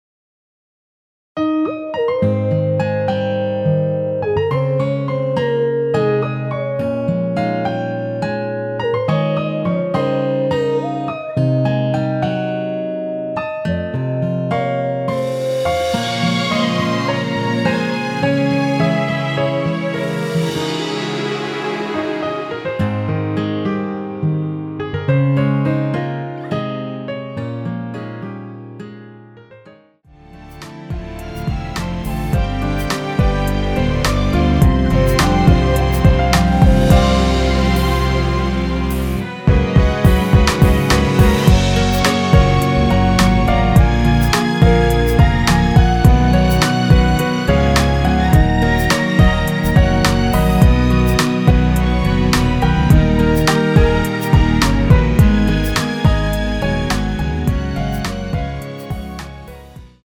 여성분이 부르실 수 있는키의 MR입니다.
원키에서(+5)올린 MR입니다.
Ab
앞부분30초, 뒷부분30초씩 편집해서 올려 드리고 있습니다.
위처럼 미리듣기를 만들어서 그렇습니다.